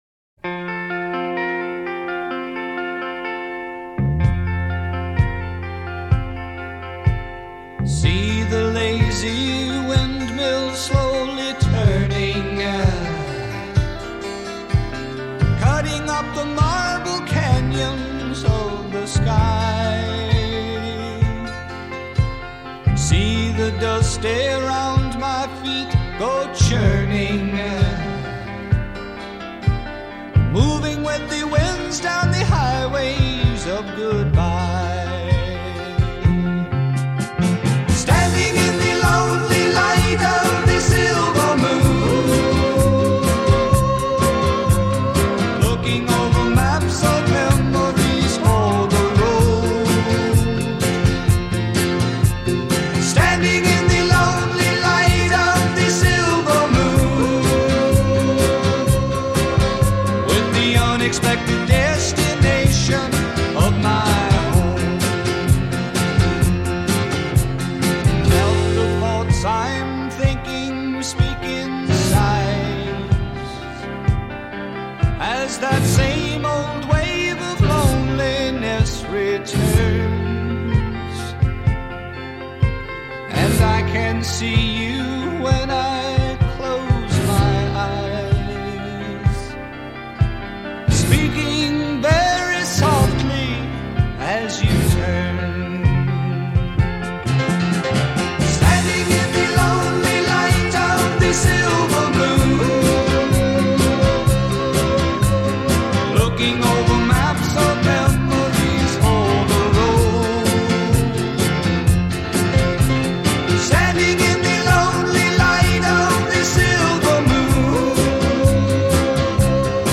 country rock